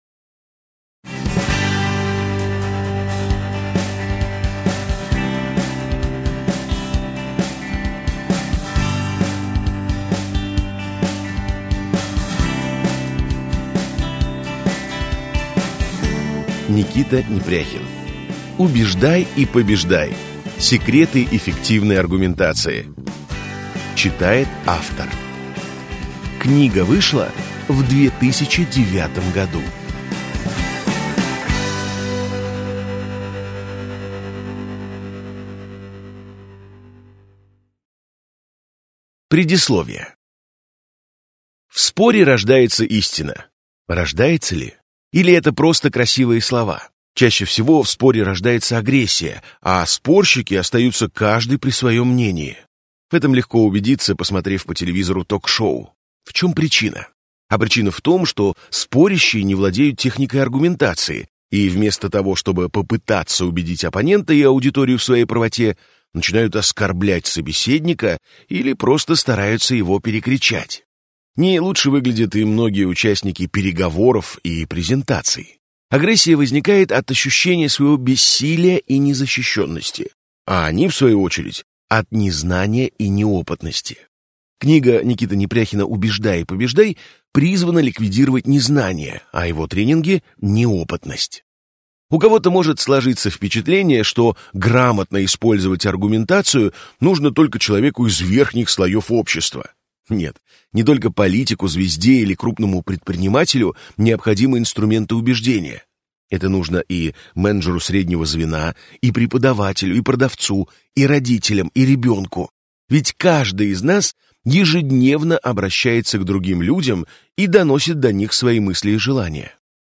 Аудиокнига Убеждай и побеждай: Секреты эффективной аргументации | Библиотека аудиокниг